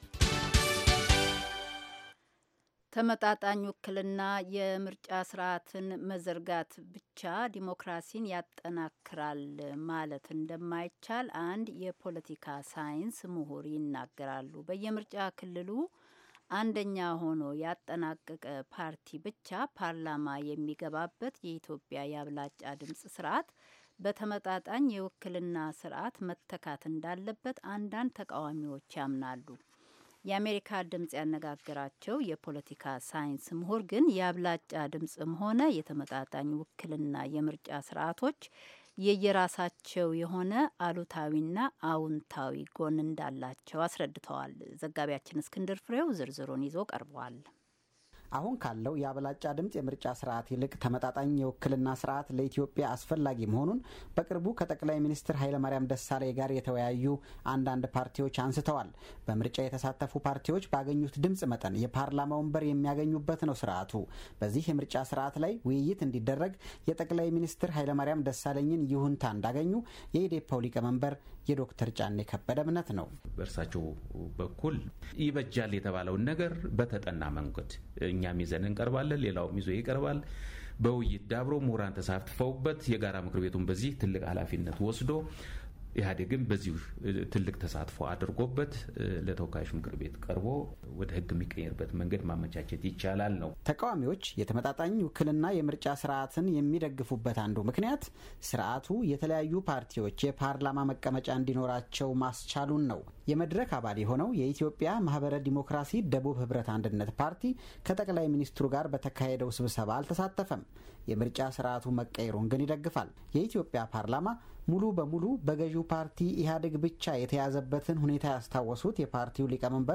በየምርጫ ክልሉ አንደኛ ሆኖ ያጠናቀቀ ፓርቲ ብቻ ፓርላማ የሚገባበት የኢትዮጵያ የአብላጫ ድምጽ ስርአት በተመጣጣኝ የውክልና ስርአት መተካት እንዳለበት አንዳንድ ተቃዋሚዎች ያምናሉ። የአሜሪካ ድምጽ ያነጋገራቸው የፖለቲካ ሳይንስ ምሁር ግን የአብለጫ ድምጽም ሆነ የተመጣጣኝ ውክልና የምርጫ ስርአቶች የየራሳቸው አሉታዊና አዎንታዊ ጎን እንዳልቸው አስረድተዋል።